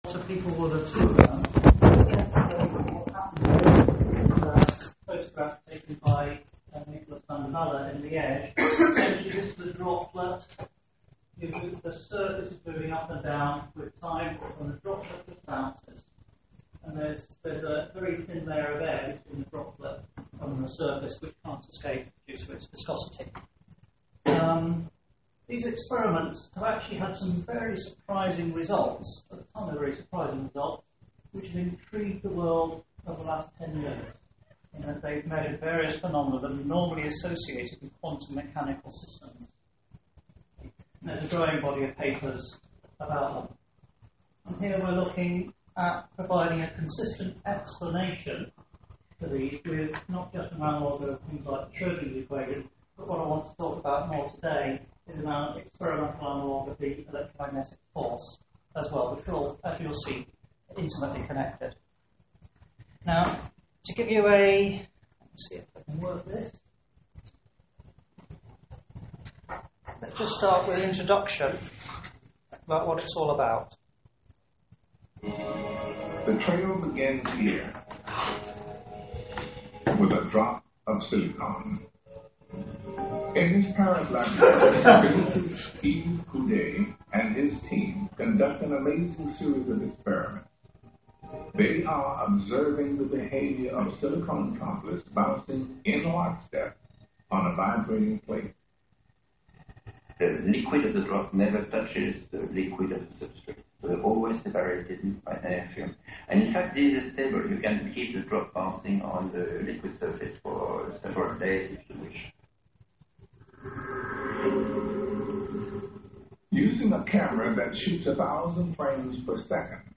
Here’s a recording of the talk; it starts off with the Couder video and the lecture proper starts 4m 22s in.